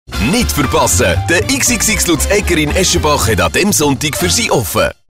Reminder-Spot
Reminder Spot PIL SUN und CEN.mp3